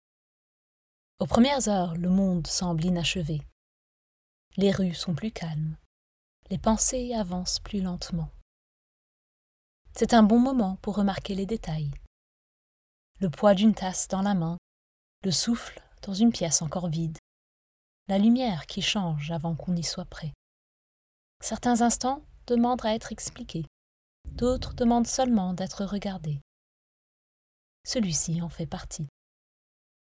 French Sample
French - European